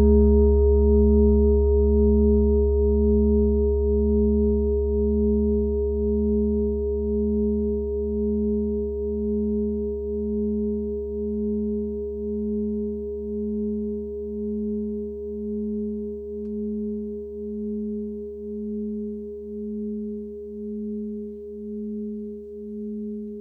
Fuß-Klangschale Nr.11
Sie ist neu und wurde gezielt nach altem 7-Metalle-Rezept von Hand gezogen und gehämmert.
Eros-Ton:
In unserer Tonleiter befindet sich diese Frequenz nahe beim "D".
fuss-klangschale-11.wav